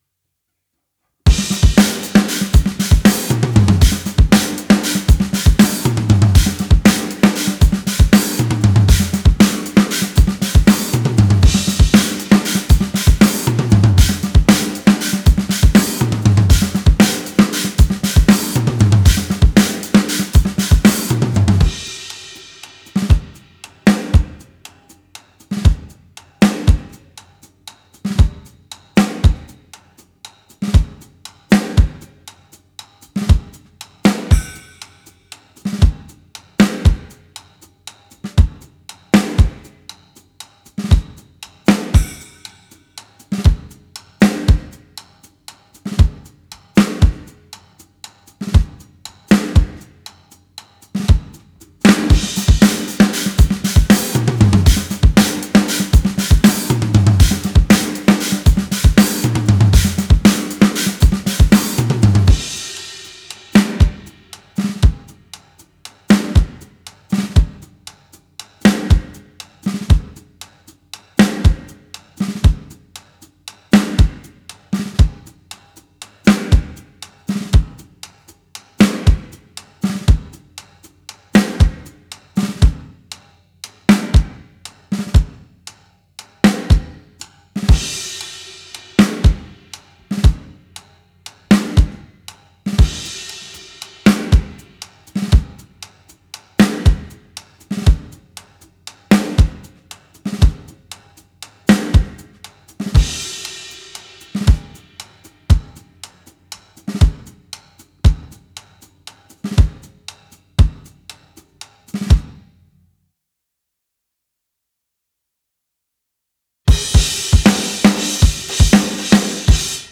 Mathrock
Genre:Mathrock, Prog
Tempo:118 BPM (5/4)
Kit:Rogers 1977 Big R 22"
Mics:14 channels